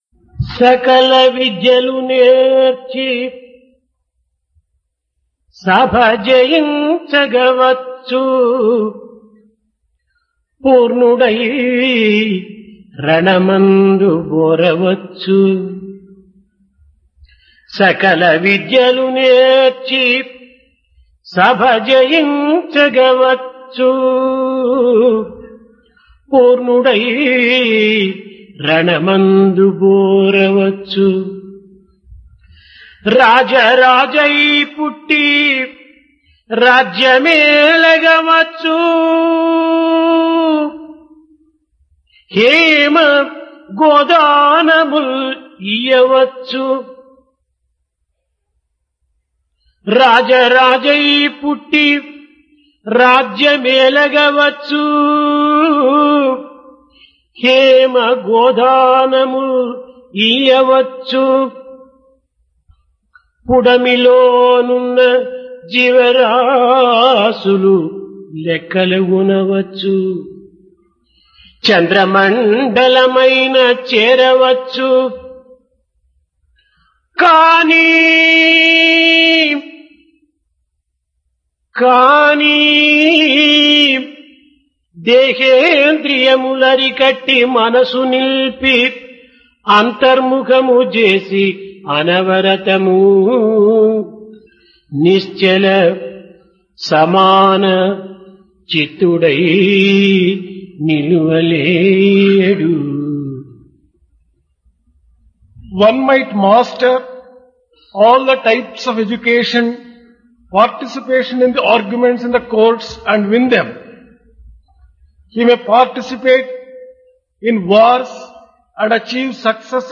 Dasara - Divine Discourse | Sri Sathya Sai Speaks
Place Prasanthi Nilayam Occasion Dasara, Vijayadasami